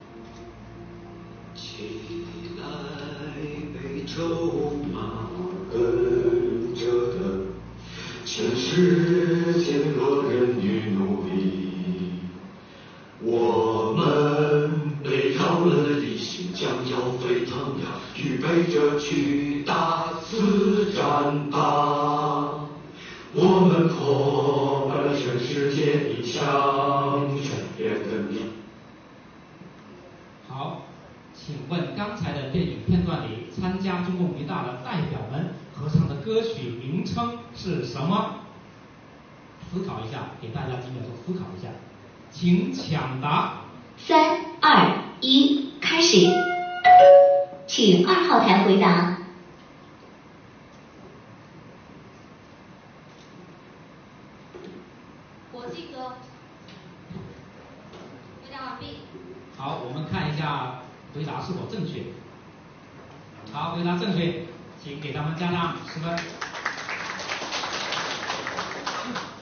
在抢答环节，随着主持人“开始抢答”的一声令下，参赛选手火力全开，争分夺秒。
抢答环节参赛选手答题